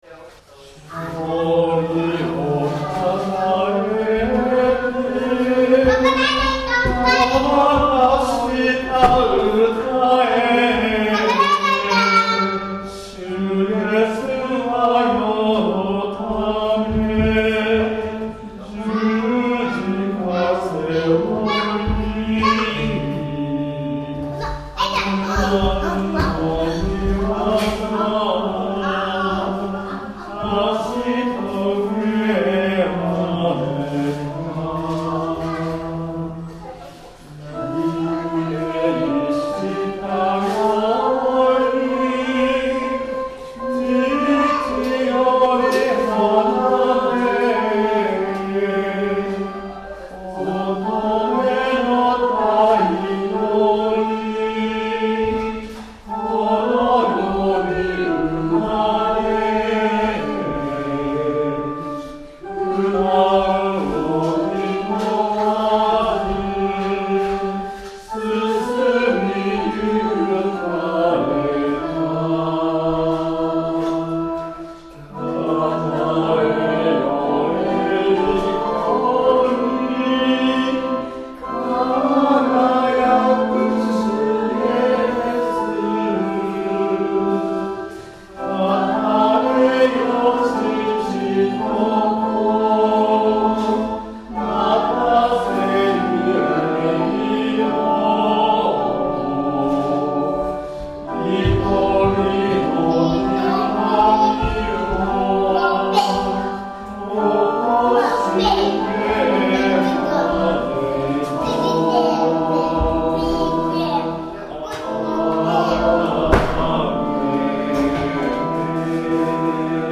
曲：単旋律聖歌 PANGE LINGUA
Temperament = Equal